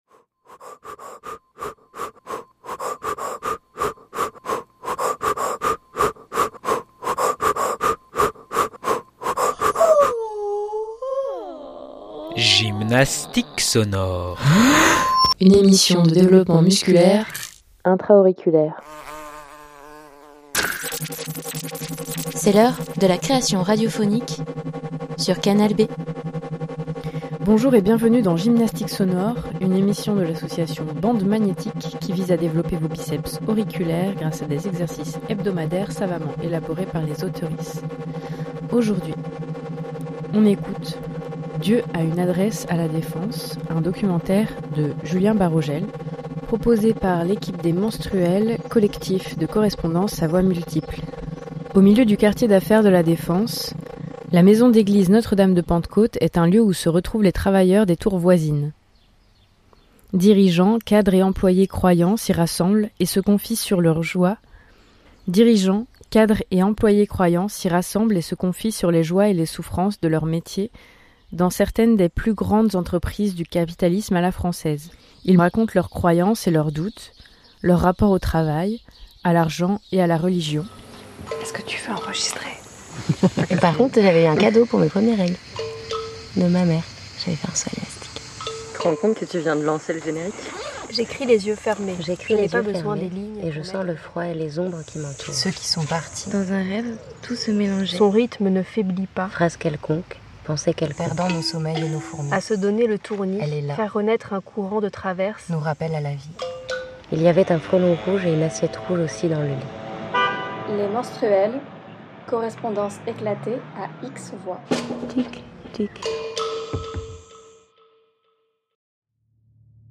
Au milieu du quartier d’affaire de La Défense, la maison d’église Notre Dame de Pentecôte est un lieu où se retrouvent les travailleurs des tours voisines. Dirigeants, cadres et employés croyants s’y rassemblent et se confient sur les joies et les souffrances de leurs métiers dans certaines des plus grandes entreprises du capitalisme à la française. Ils me racontent leurs croyances et leurs doutes, leurs rapports au travail, à l’argent et à la religion, au moment où tout ce qui fait leur monde me semble voué à disparaitre.